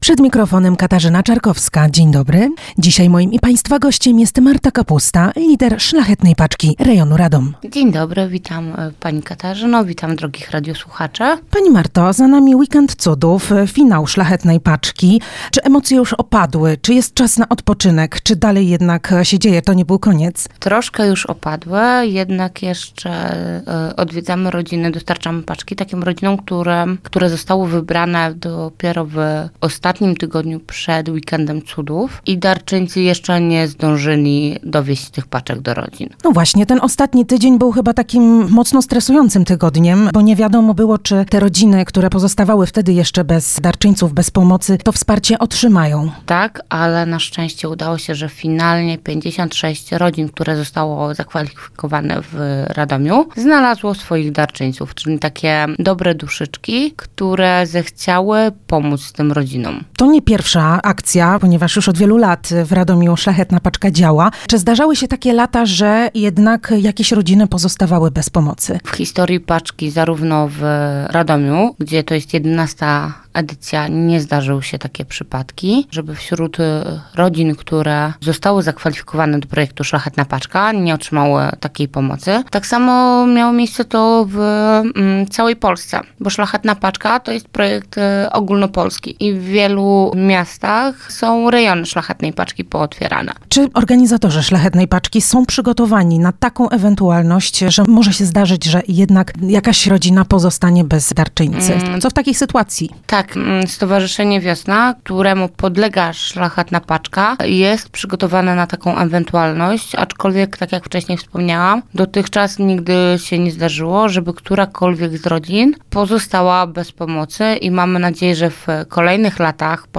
Gość Dnia